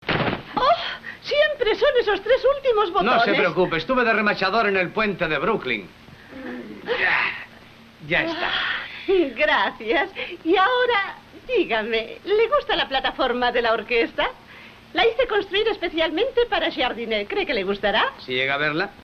En castellano, tenemos una pista sonora también monoaural, Dolby Digital 1.0 a 192 kbps.
También nos resulta bastante chocante la voz del enano (doblada por un niño, aunque al fin y al cabo en la pista original también es la voz de un niño).
aquí una muestra del doblaje, para poderlo comparar con el de las otras películas de los Marx.
El nivel de ruido de fondo no es demasiado molesto y el volumen resulta bastante regular (aunque presenta una punta en un fragmento musical en 11' 36"); además, no llega a distorsionar en ningún momento.
Sonido regular, con algo de ruido de fondo